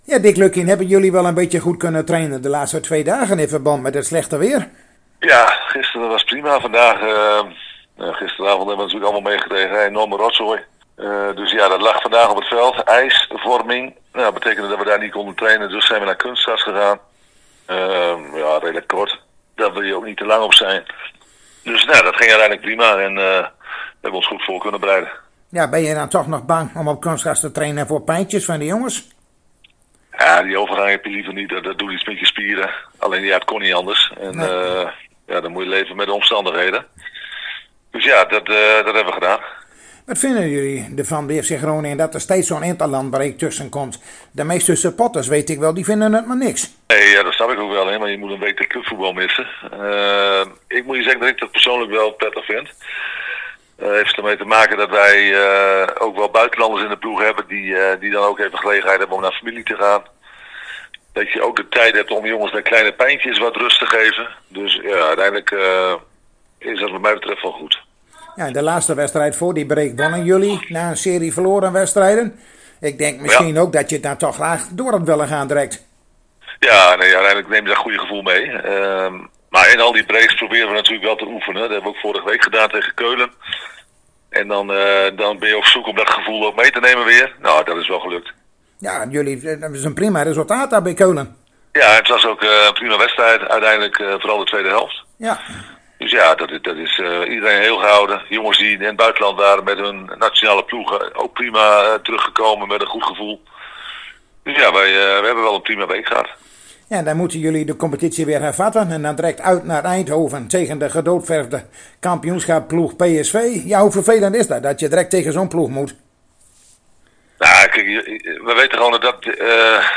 Morgenavond speelt FC Groningen de lastige uitwedstrijd tegen gedoodverfd kampioen PSV. Om 21.00 uur trappen beide ploegen af in het Phillips Stadion en wij spraken zojuist met Dick Lukkien over die wedstrijd.